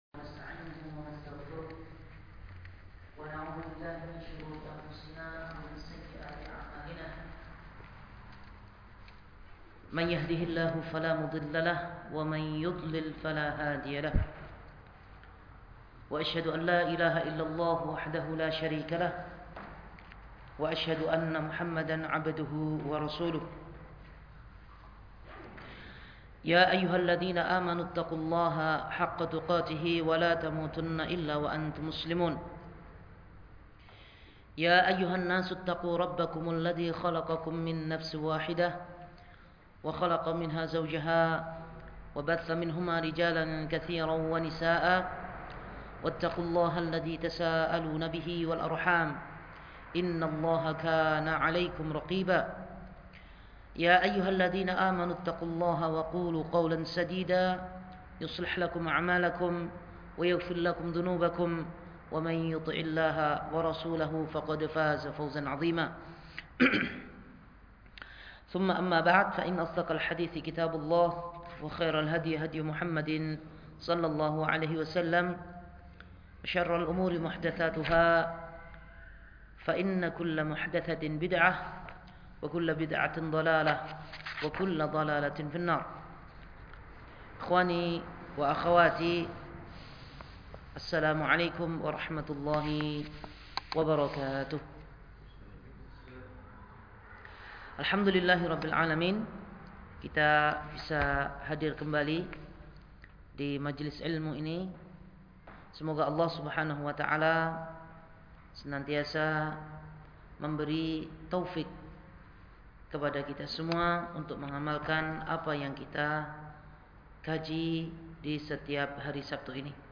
Kajian Sabtu
Barwa Village, 22 Rajab 1445 / 3 Februari 2024